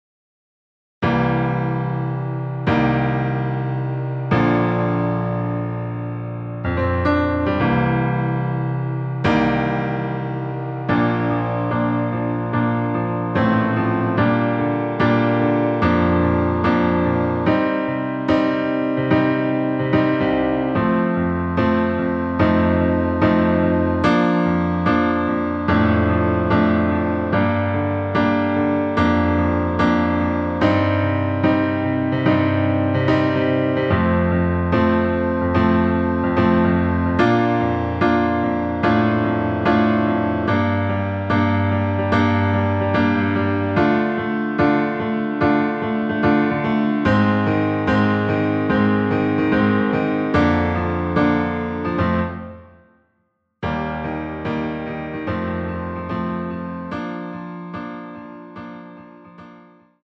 -2)내린 MR
Bb
앞부분30초, 뒷부분30초씩 편집해서 올려 드리고 있습니다.
중간에 음이 끈어지고 다시 나오는 이유는
곡명 옆 (-1)은 반음 내림, (+1)은 반음 올림 입니다.